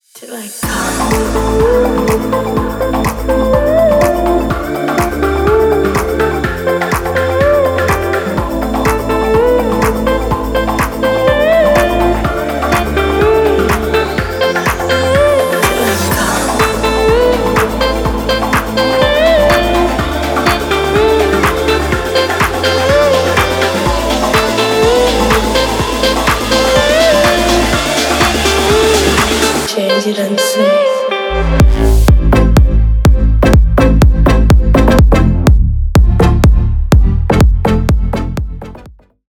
Ремикс # Электроника
без слов